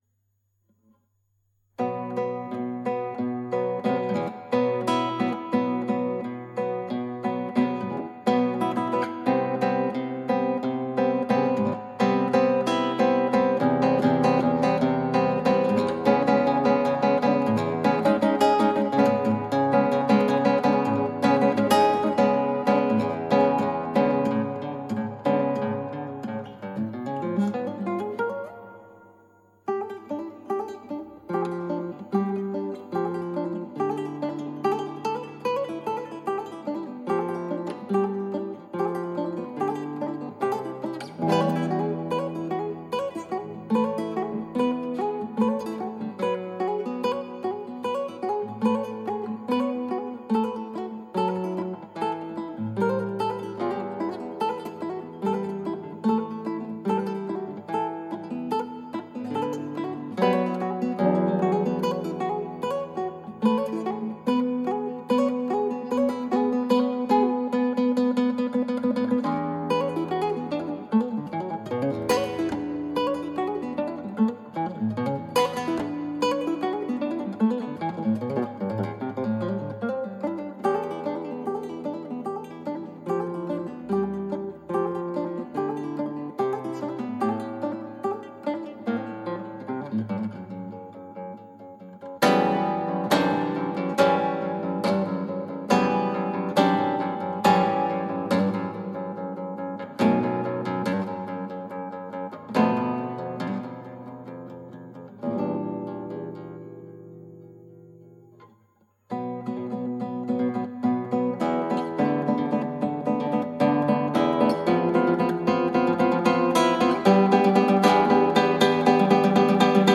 クラシックギター　ストリーミング　コンサート
ヴィラロボスのエチュードです。
この１０番は変拍子バリバリの曲で、とっ散らかりやすいんです。
左手全部スラーですので右手はもっぱら消音に気を使います。
技術的にはそのスラーがすべてといっていいほど肝の曲です。
中間部、四分音符分多く弾いてるトコとラストのシンコペきつい場所でまちがってるんです。